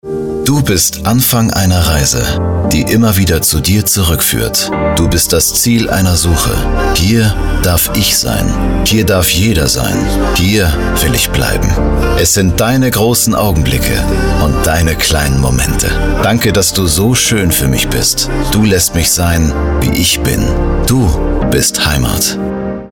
dunkel, sonor, souverän, markant
Mittel plus (35-65)